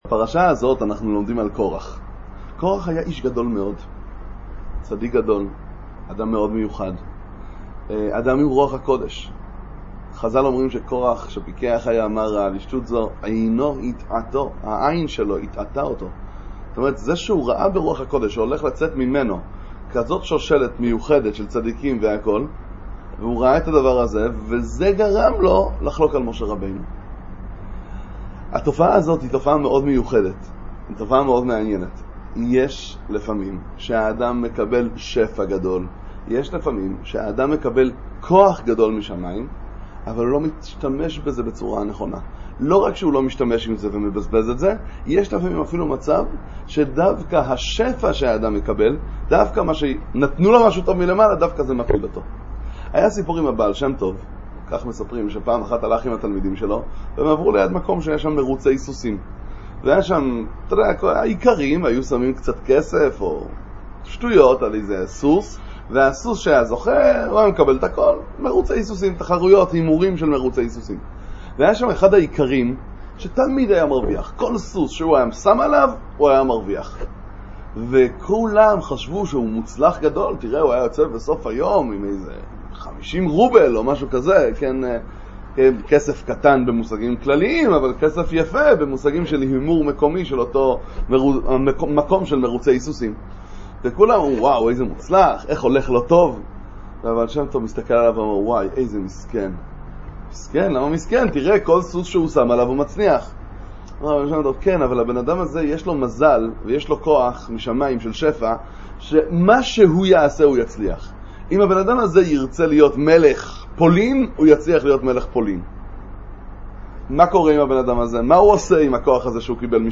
לנצל נכון את המתנה – דבר תורה קצר לפרשת קורח